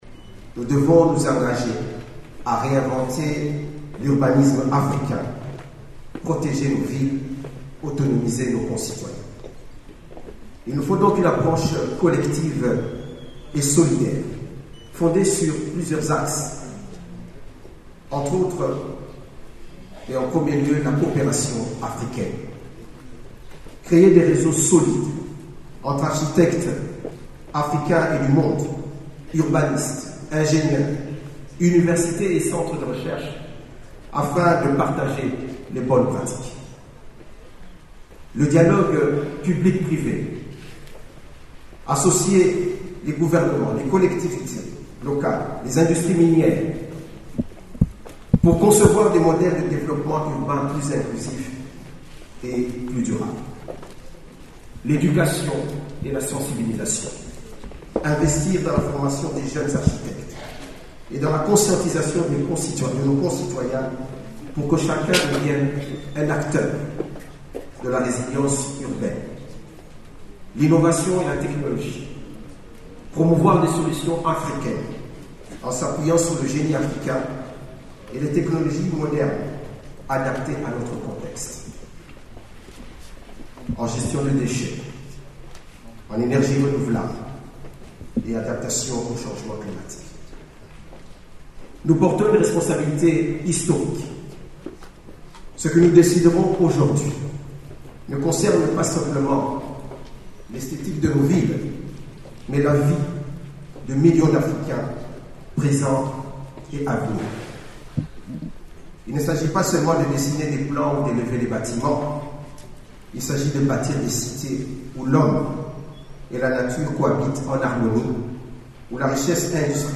Le ministre d’État en charge de l’Urbanisme, Alexis Gisaro, a souligné la nécessité de « réinventer l’urbanisme africain » afin de mieux protéger les villes du continent face aux défis contemporains.